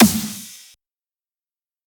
soft-hitfinish.ogg